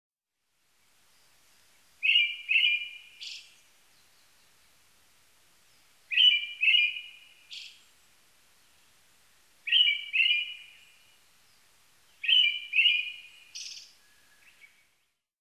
アカハラ　Turdus chrysolausツグミ科
日光市稲荷川中流　alt=730m  HiFi --------------
Mic.: audio-technica AT822
他の自然音：　 キジ・ウグイス